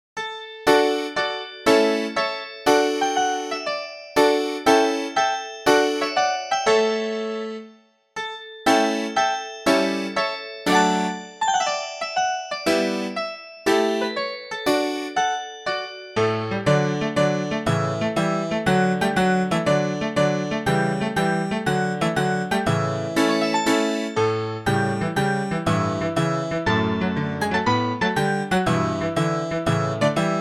Converted from .mid to .ogg
Fair use music sample